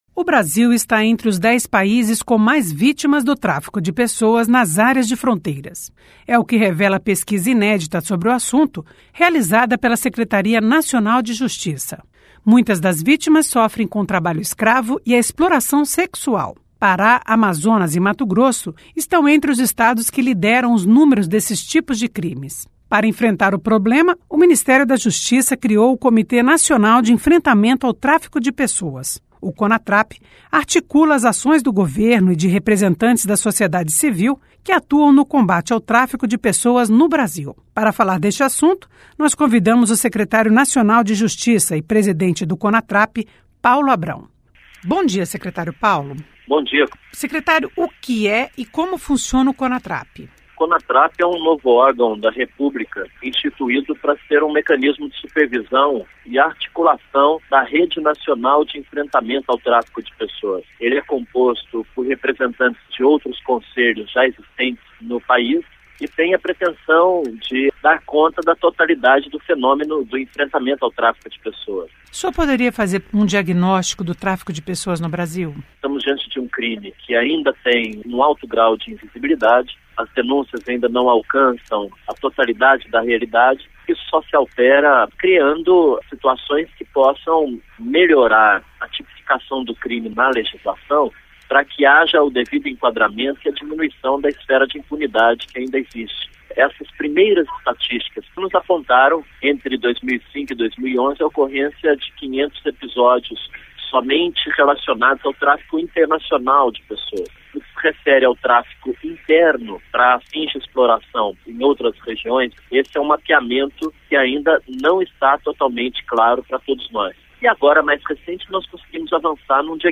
Entrevista com o secretário Nacional de Justiça e presidente do Comitê Nacional de Enfrentamento ao Tráfico de Pessoas - Conatrap, Paulo Abrão.